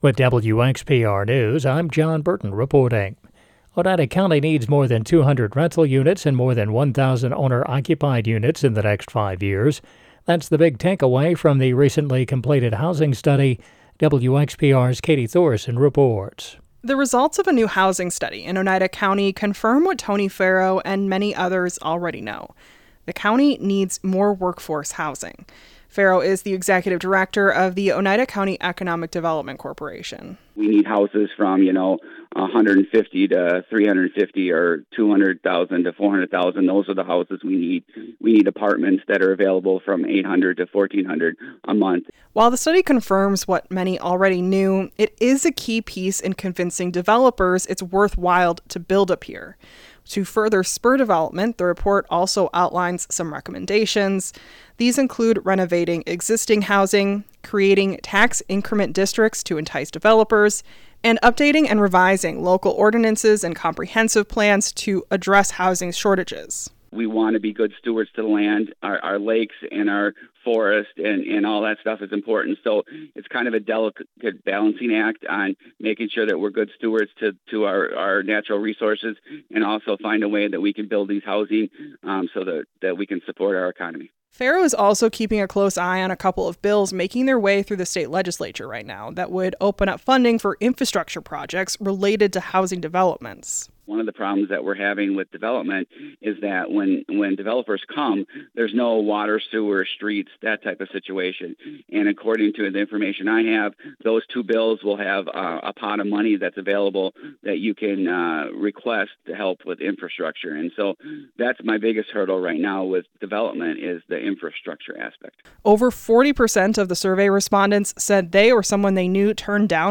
The Extra is WXPR’s daily news podcast. Get the news you need to stay informed about your local community – all in six minutes or less.